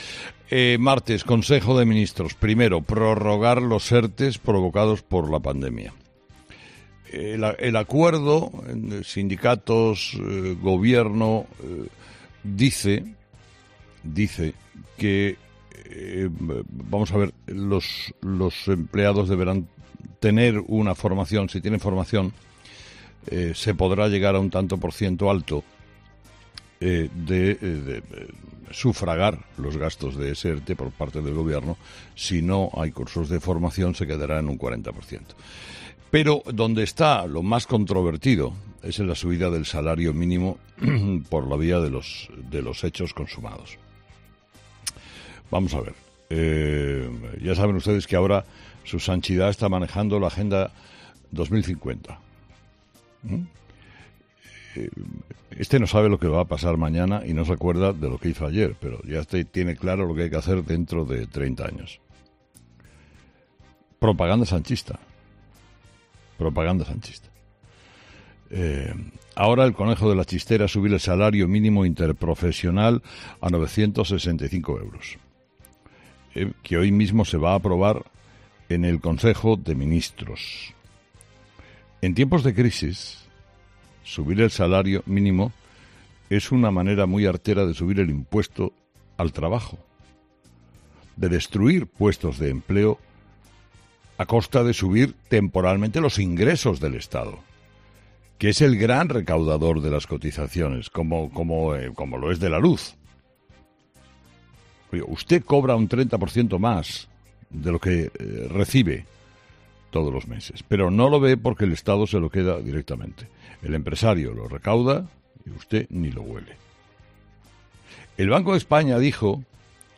Carlos Herrera,, director y presentador de 'Herrera en COPE' ha comenzado el programa analizando las principales claves de la jornada, que pasaban por asuntos como la situación del volcán de Cumbre Vieja, que ha erupcionado con fuerza durante la noche provocando nuevas riadas de lava más líquida, situándose a 1.200 metros del mar.